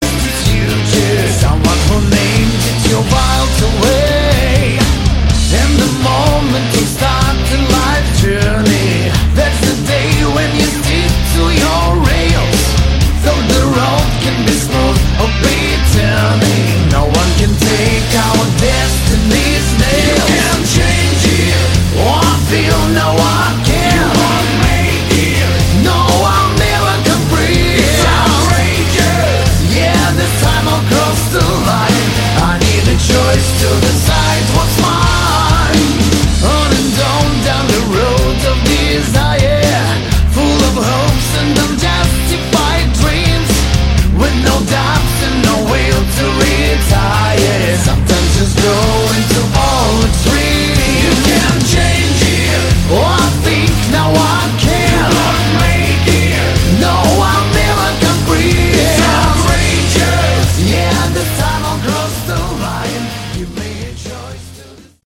Category: Hard Rock
guitar, backing vocals
lead vocals
bass, backing vocals
drums